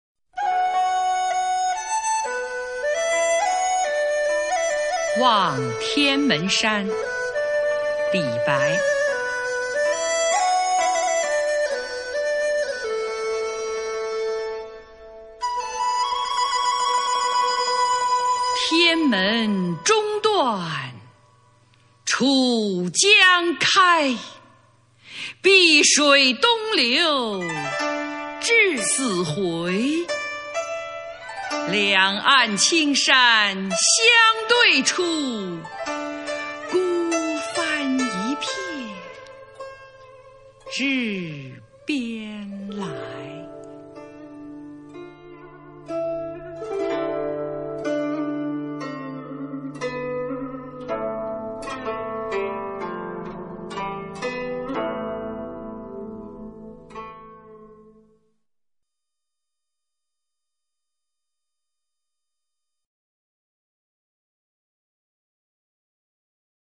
首页 视听 经典朗诵欣赏 群星璀璨：中国古诗词标准朗读（41首）